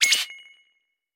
Звуки всплывающего окна
Электронный звук появления объекта на видео